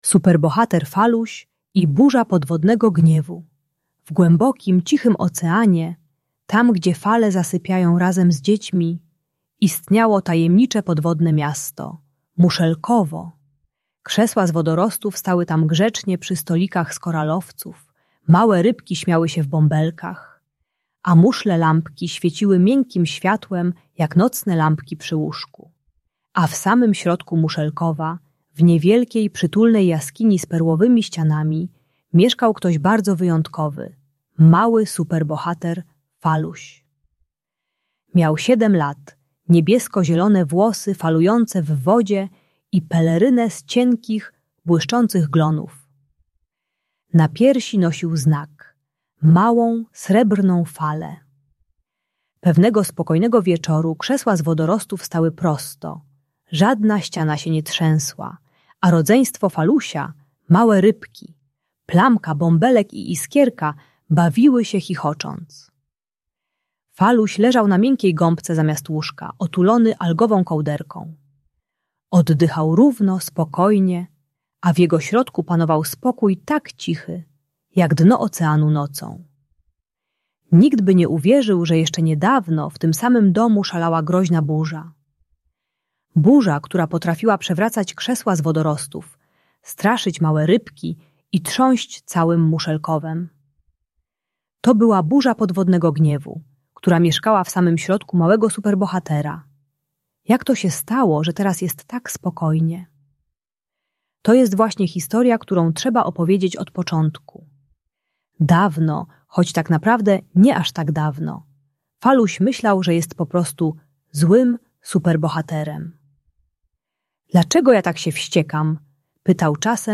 Superbohater Faluś - Bunt i wybuchy złości | Audiobajka